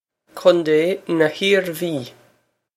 Cun-tay na Heer-vee
This is an approximate phonetic pronunciation of the phrase.